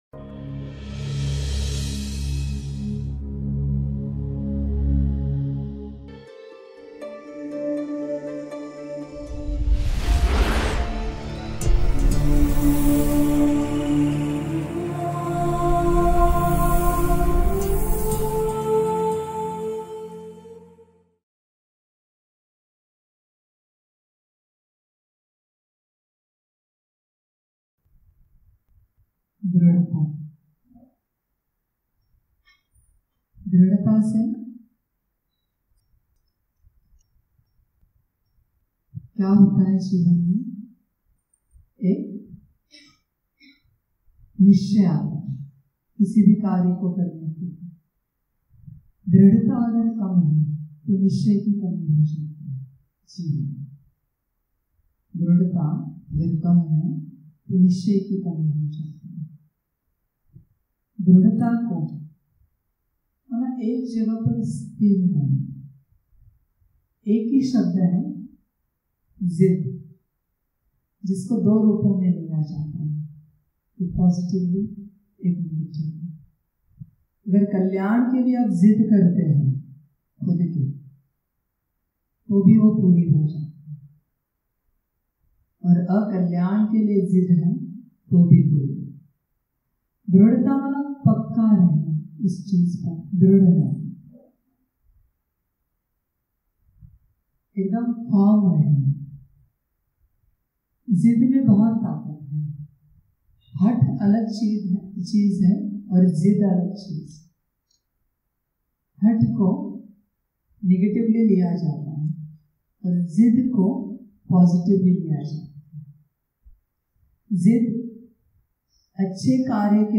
क्लास - सुखी जीवन के लिए आत्मा के सभी गुणों और शक्तियों का महत्त्व और उनको भरपूर करने की विधि — Magic Diamond of Group